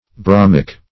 Bromic \Bro"mic\, a. (Chem.)